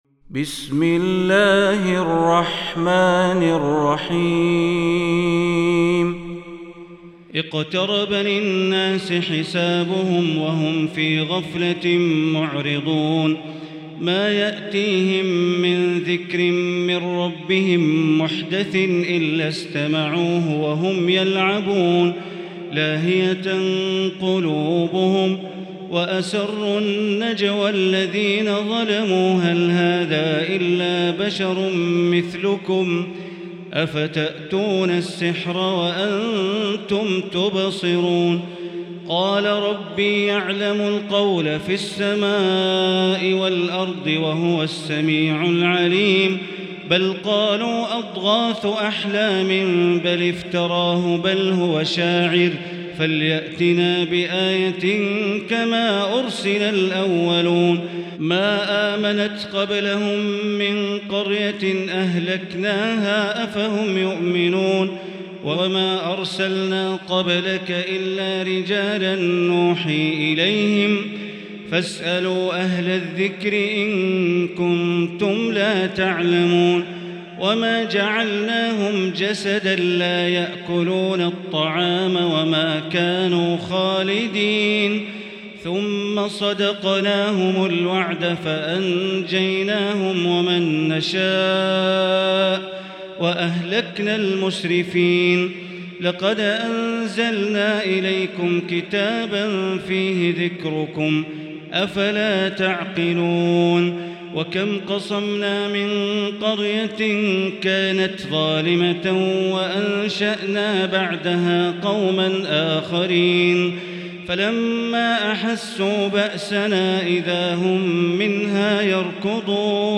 المكان: المسجد الحرام الشيخ: معالي الشيخ أ.د. بندر بليلة معالي الشيخ أ.د. بندر بليلة معالي الشيخ أ.د. عبدالرحمن بن عبدالعزيز السديس الأنبياء